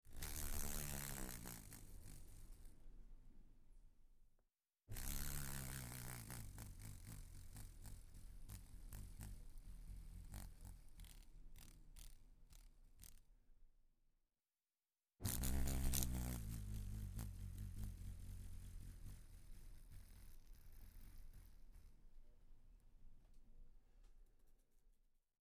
Звуки шершня
Шум крыльев шершня